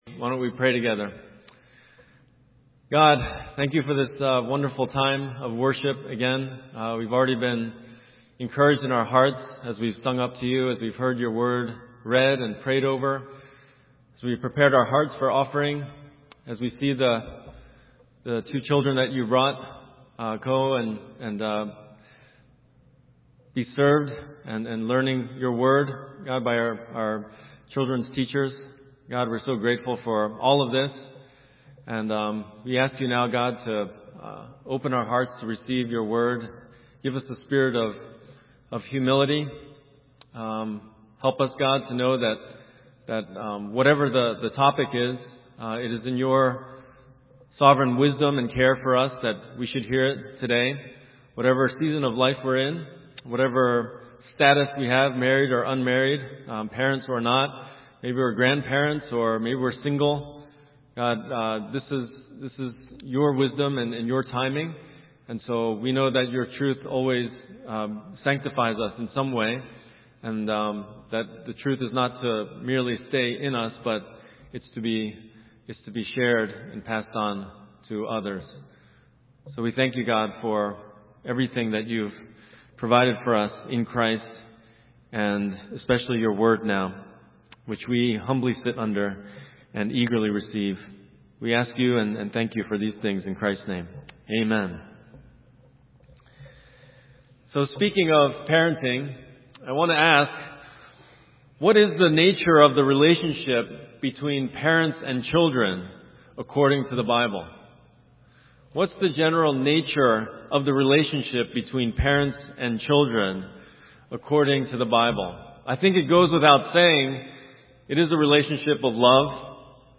Sermon Theme: God has given clear instructions to both slaves and masters in their relation to one another, underscoring their common position as slaves of the Lord Jesus Christ.